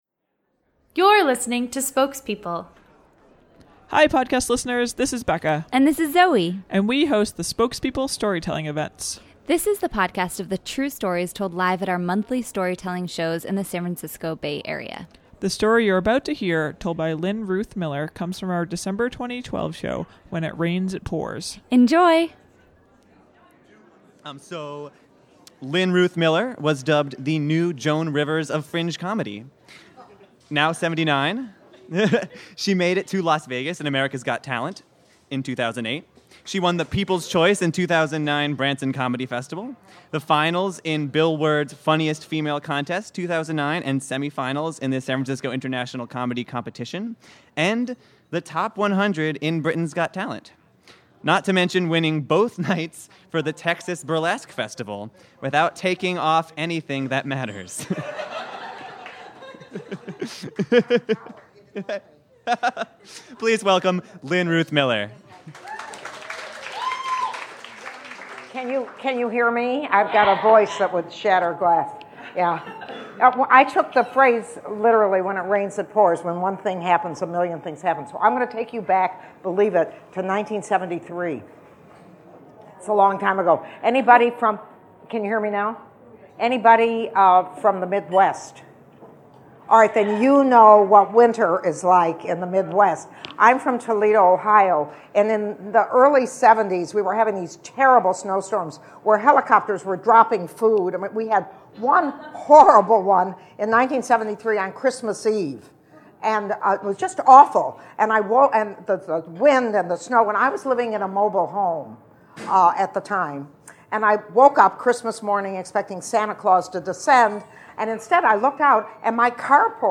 Homepage / Podcast / Storytelling
Not exactly an auspicious opening to a story, but it is particularly descriptive of the season of ups and downs that started with an insurance adjuster and ended with a Christmas in July. This story of the consequences of living in the Midwest comes from our December 2012 show, When It Rains, It Pours.